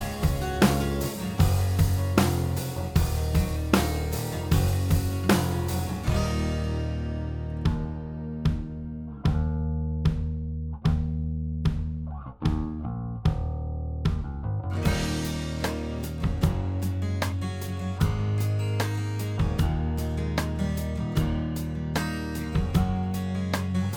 Minus Electric Guitars Rock 4:06 Buy £1.50